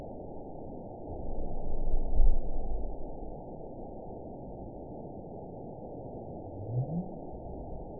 event 920750 date 04/07/24 time 08:28:34 GMT (1 year, 8 months ago) score 9.57 location TSS-AB02 detected by nrw target species NRW annotations +NRW Spectrogram: Frequency (kHz) vs. Time (s) audio not available .wav